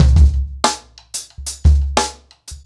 Expositioning-90BPM.5.wav